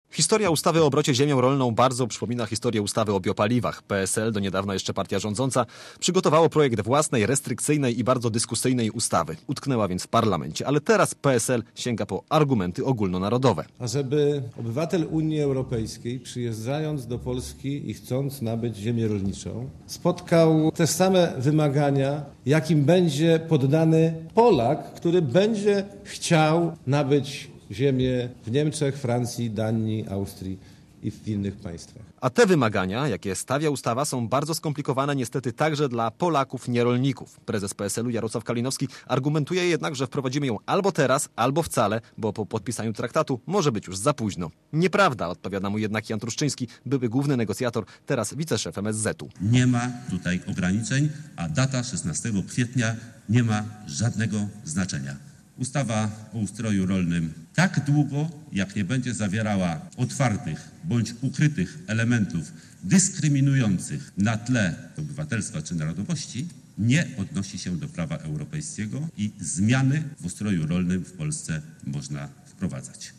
Relacja reportera Radia Zet (577Kb)